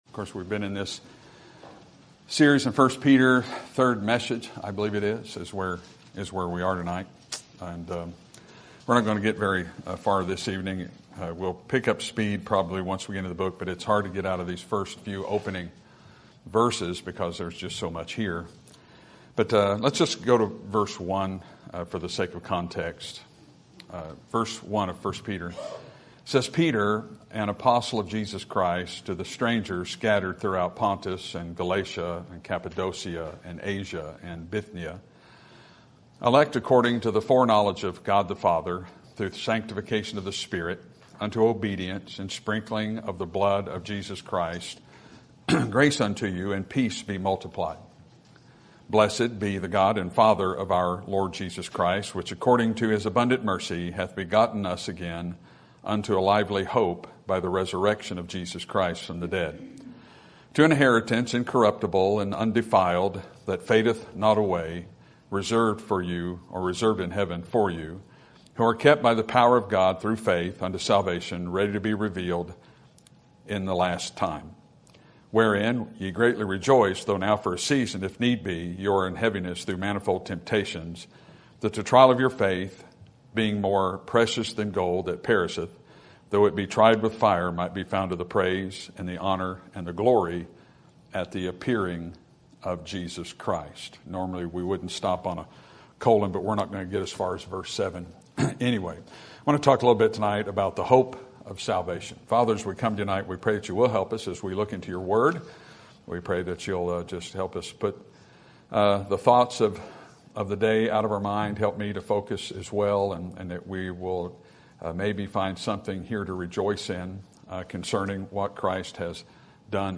Sermon Topic: Book of 1 Peter Sermon Type: Series Sermon Audio: Sermon download: Download (17.75 MB) Sermon Tags: 1 Peter Hope Peter Salvation